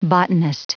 Prononciation du mot botanist en anglais (fichier audio)
Prononciation du mot : botanist